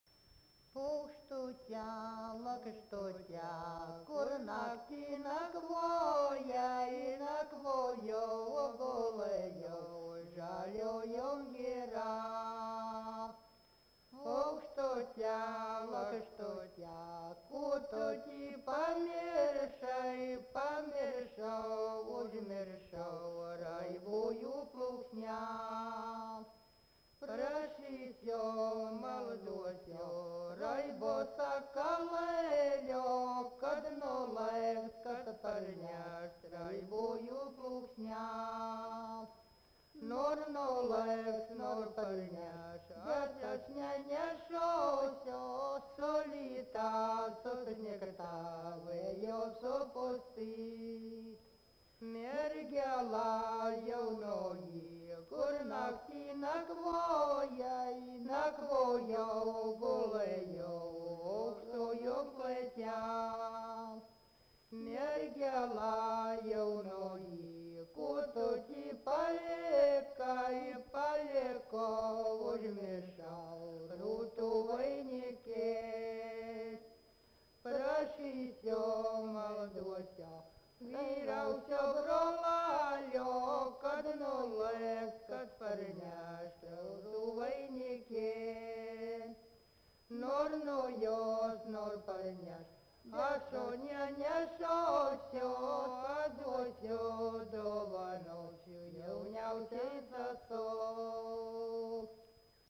Subject daina
Erdvinė aprėptis Viečiūnai
Atlikimo pubūdis vokalinis